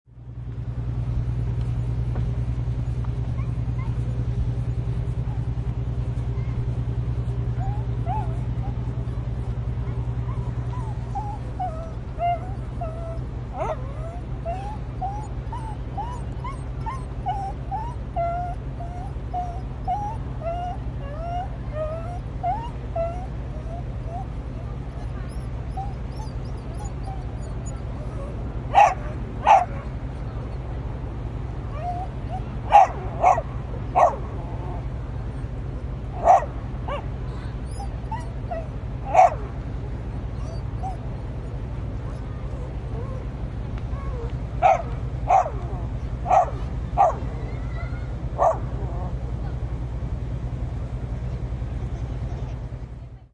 Harbour Scene 2 Botón de Sonido